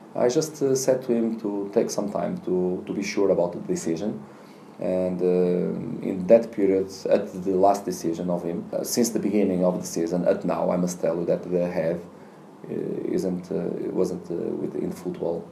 Sheffield Wednesday manager Carlos Carvalhal says he is really disappointed that midfielder Jeremy Helan is leaving the club for religious reasons.